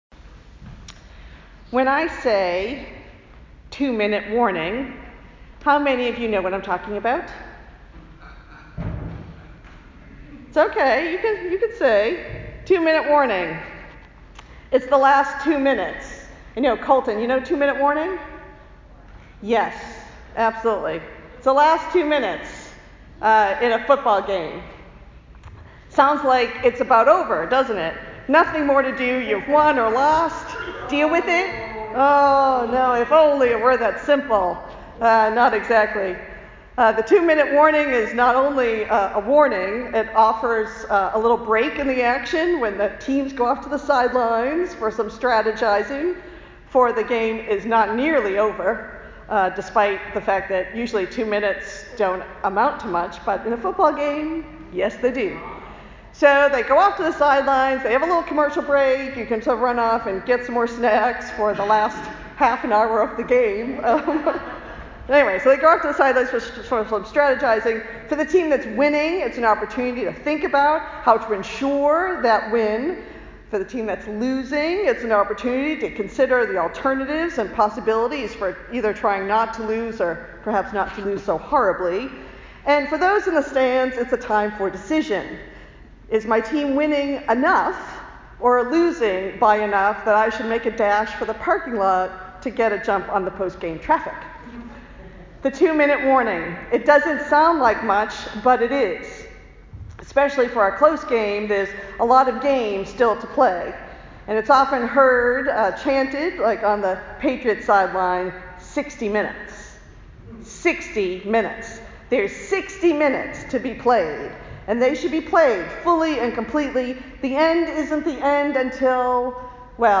sermonadventone2017.mp3